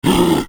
Scary Monster Growl Roar 3 Sound Effect Download: Instant Soundboard Button